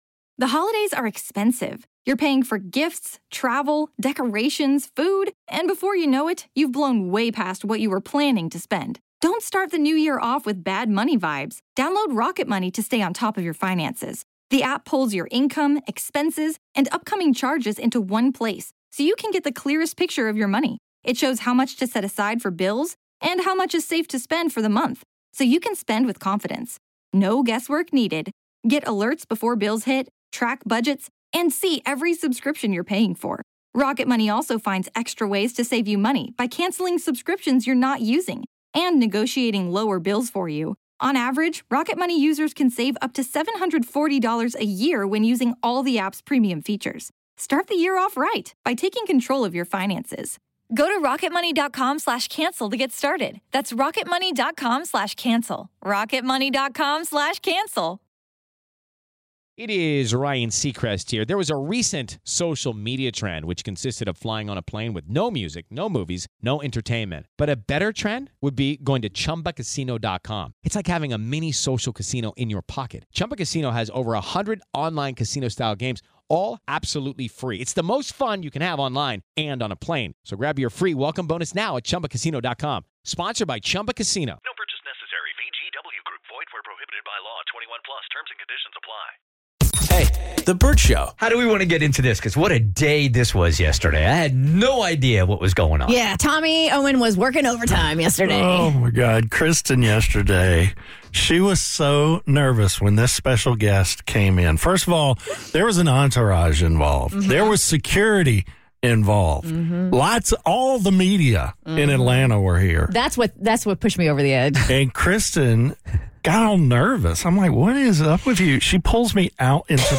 Mayor Andre Dickens is in studio